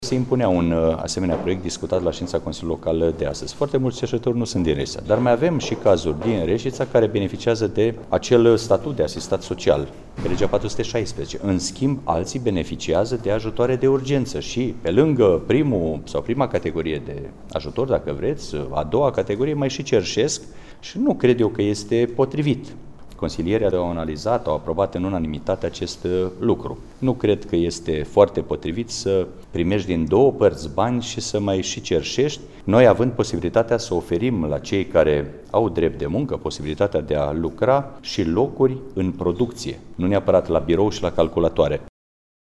Primarul Mihai Stepanescu: